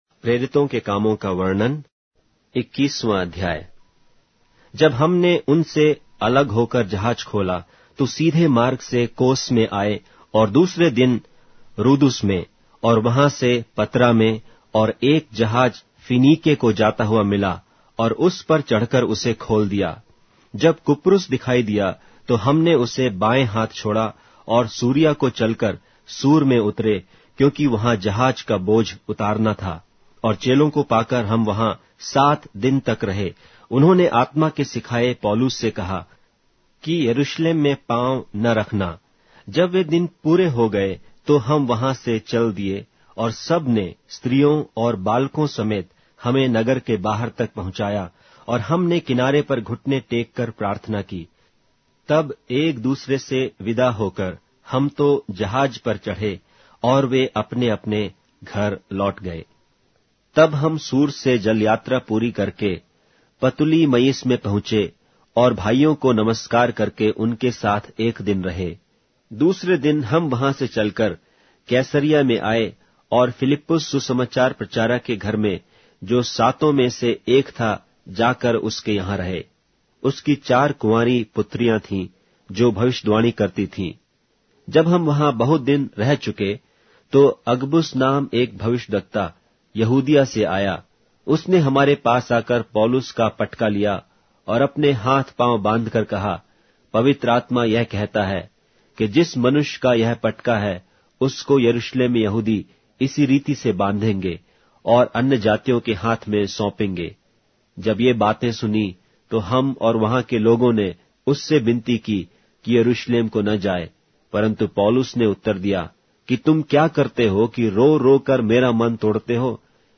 Hindi Audio Bible - Acts 3 in Ncv bible version